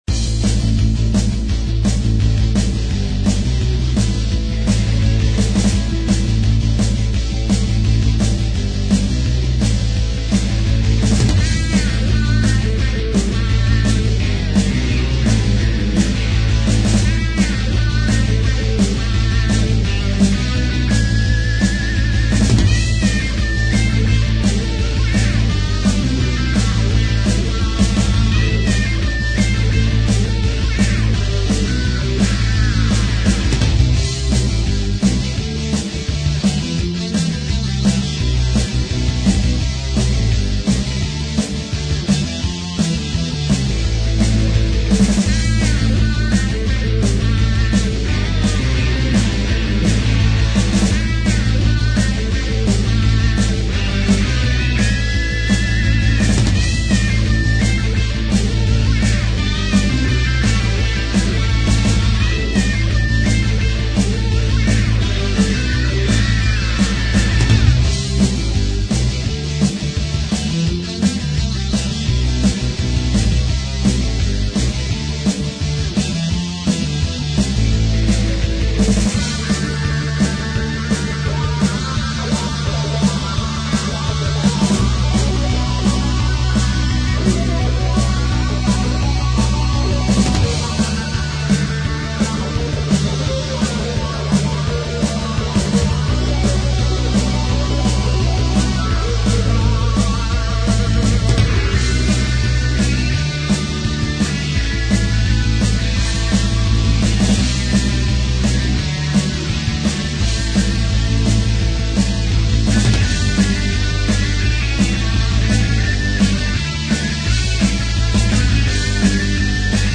The songs below are draft mixes of music